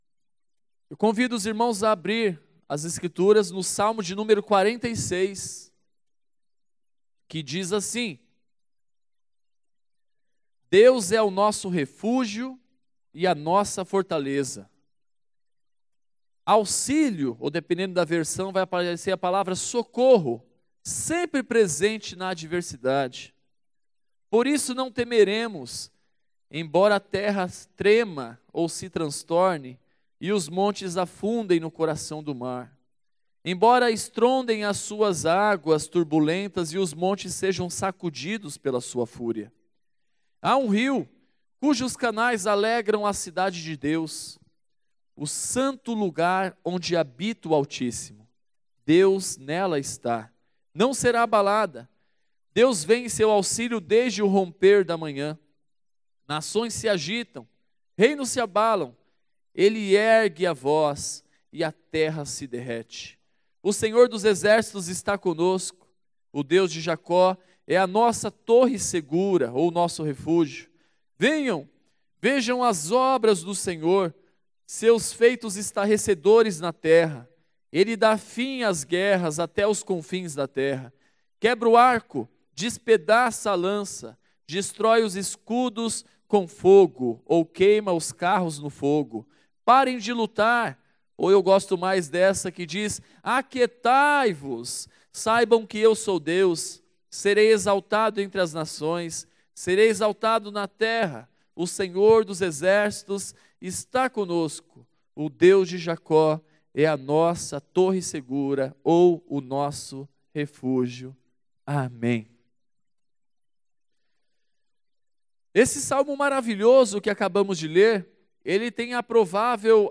Mensagens